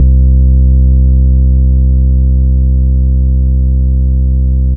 Dome Bass 65-04.wav